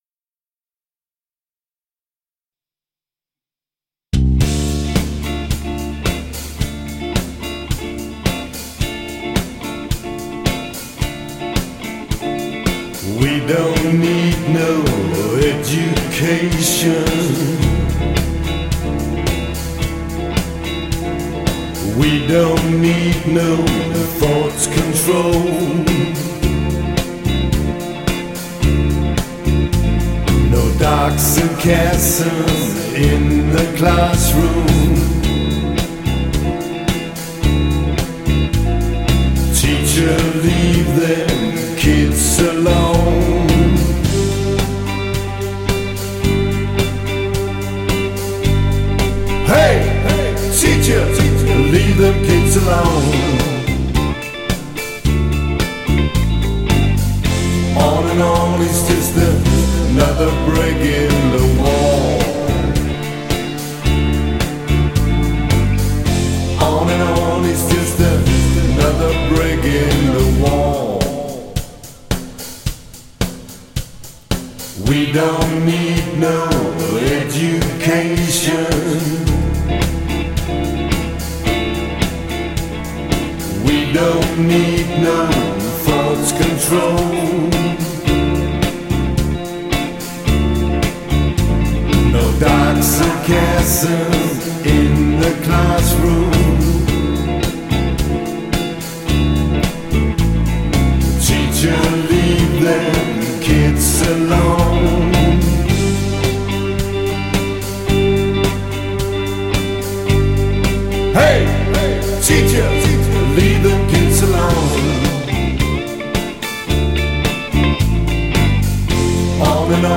• Coverband
• Duo eller trio